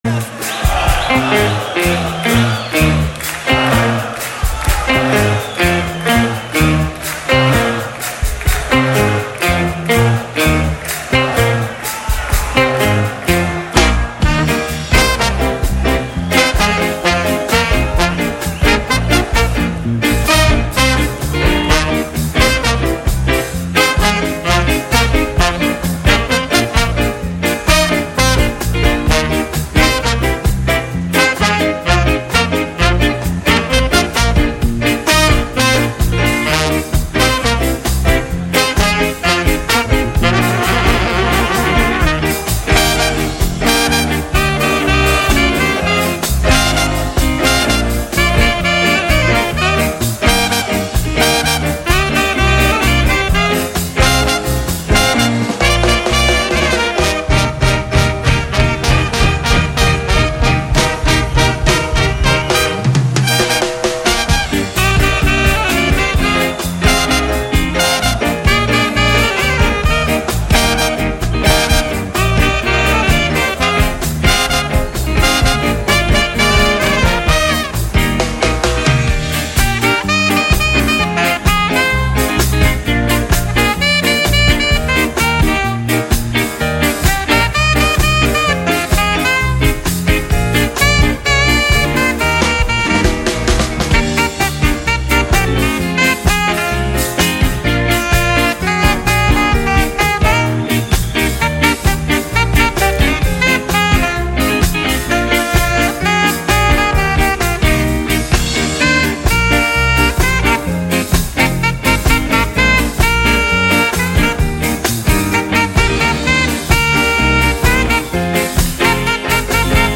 Rudy's Back est une émission de Ska, de Punk depuis 1995.